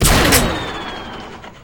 pshoot2.ogg